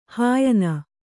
♪ hāyana